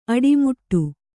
♪ aḍimuṭṭu